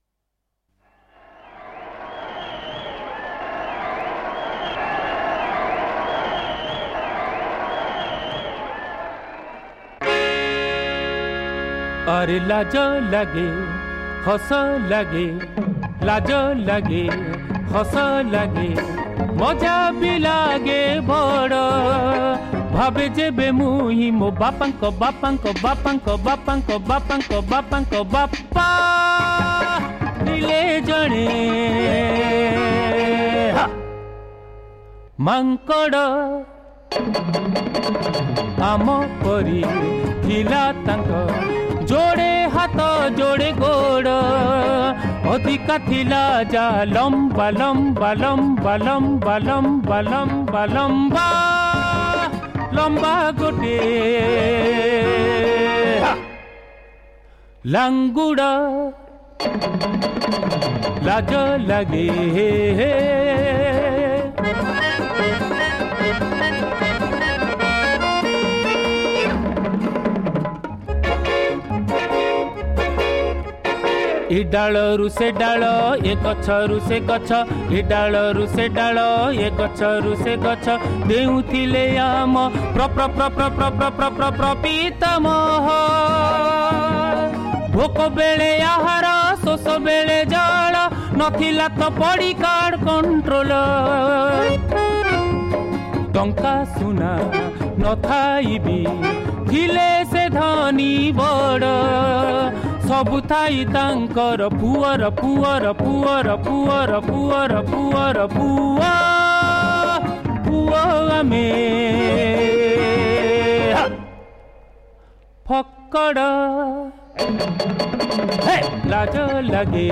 Oriya Modern Songs